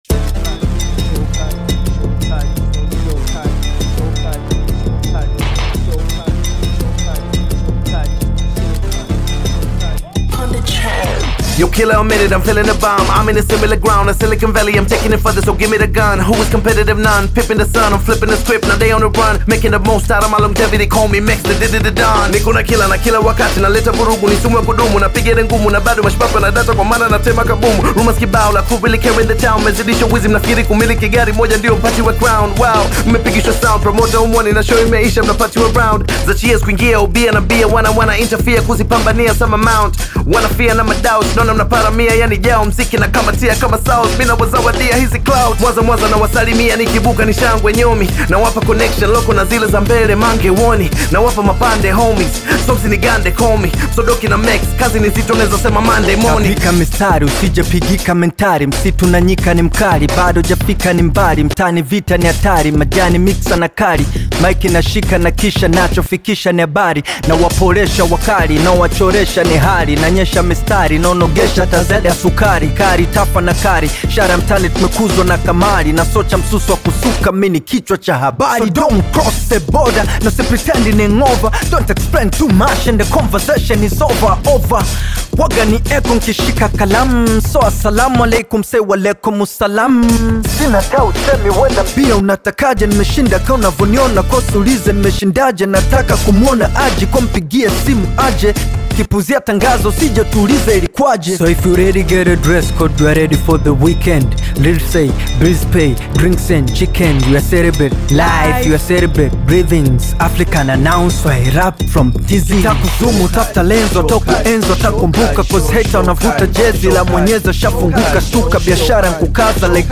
Tanzanian Hip Hop